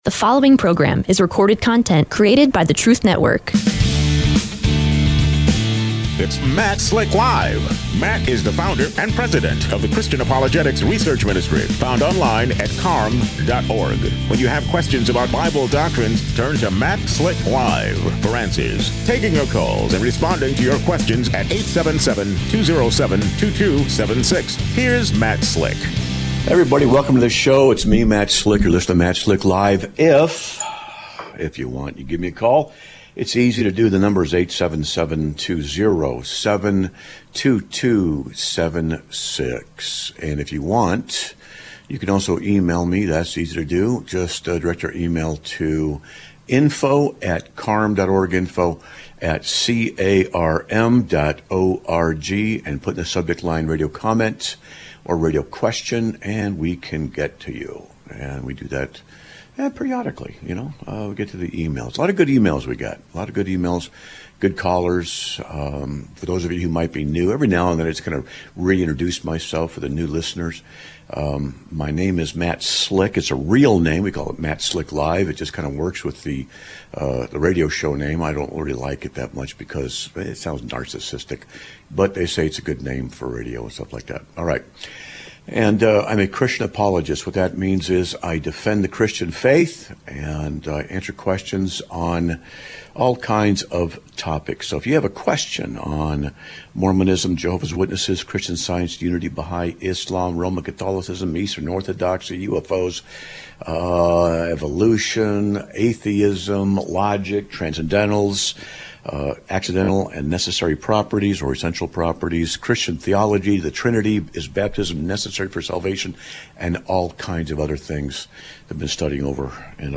A Caller Discusses Evangelism and The Loss of a Friend